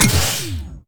laser-turret-deactivate-02.ogg